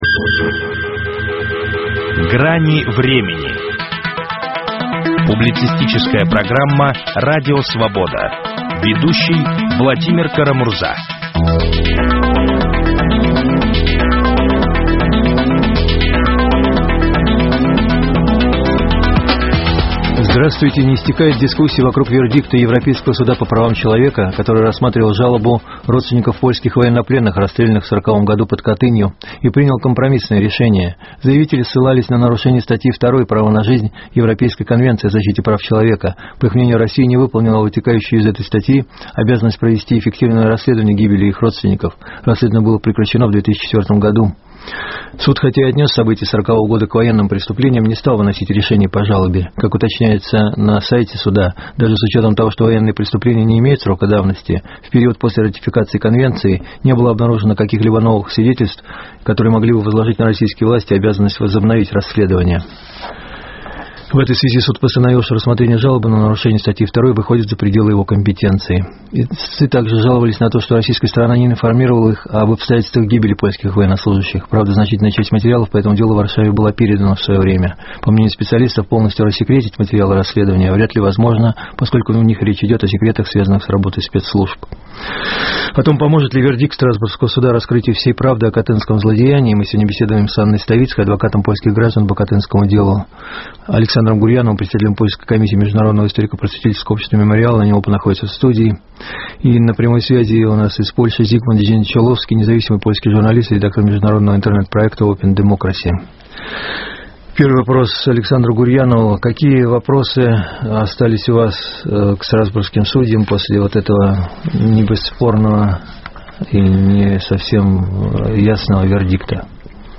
Поможет ли вердикт Страсбургского суда раскрытию всей правды о катынском злодеянии? Об этом беседуем с адвокатом